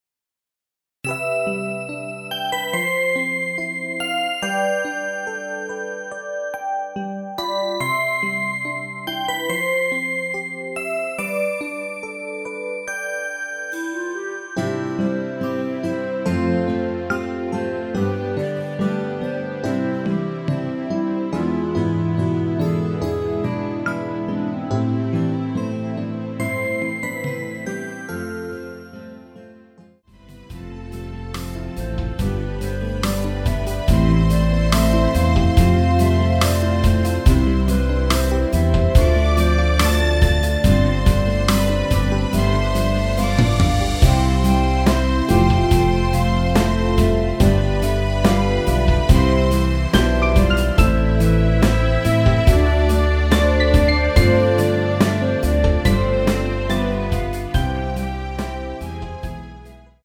멜로디 포함된 MR 입니다.
앞부분30초, 뒷부분30초씩 편집해서 올려 드리고 있습니다.
중간에 음이 끈어지고 다시 나오는 이유는